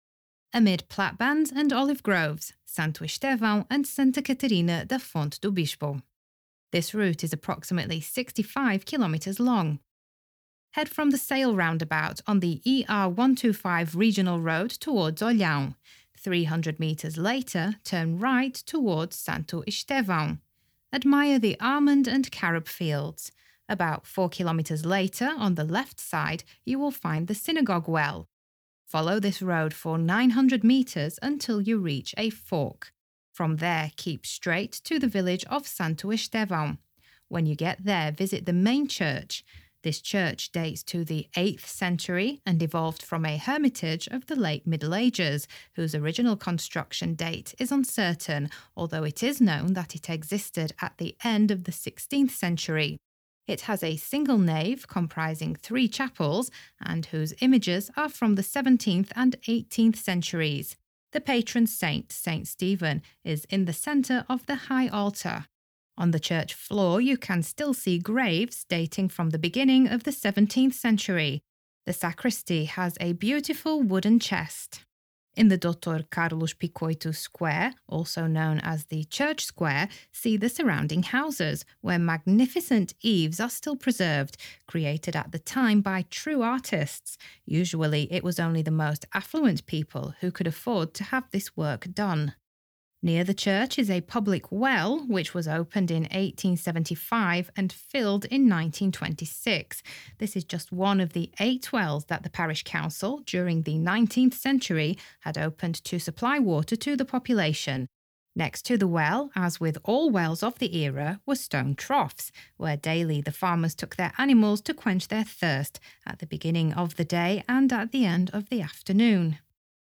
Audioguide_ Amid platbands and olive groves